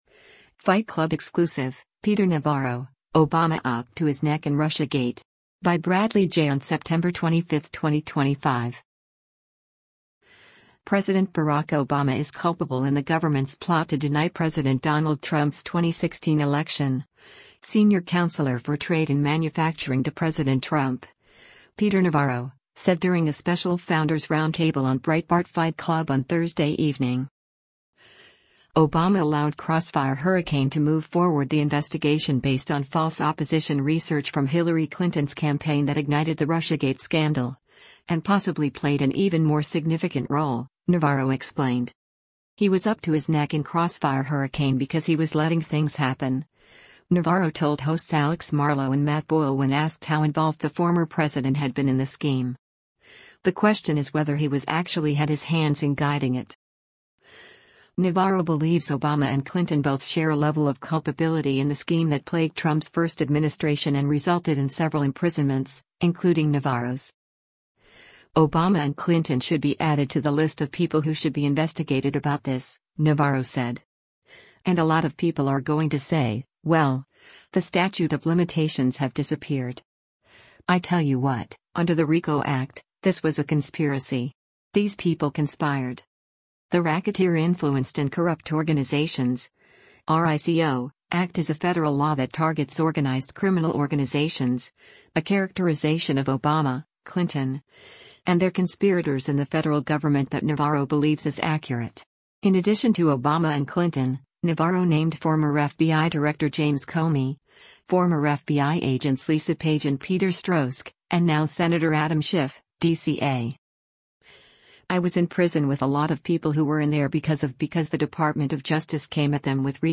President Barack Obama is culpable in the government’s plot to deny President Donald Trump’s 2016 election, Senior Counselor for Trade and Manufacturing to President Trump, Peter Navarro, said during a special Founders Roundtable on Breitbart Fight Club on Thursday evening.